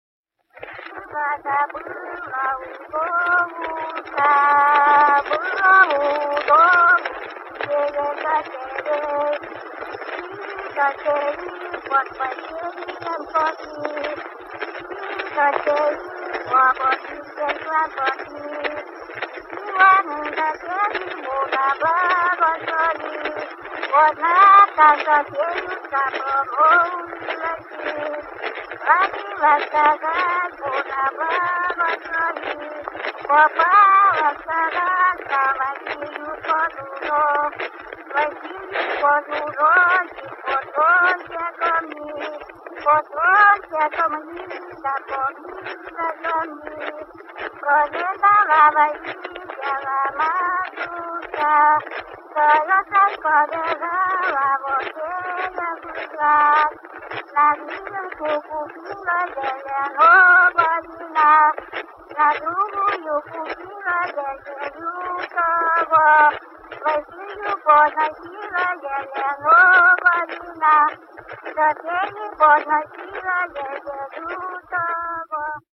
Биографические данные севернорусских сказителей и фольклорные тексты, записанные в фольклорно-этнографических экспедициях на Русский Север
Аудиозаписи Василий и Софья (духовный стих).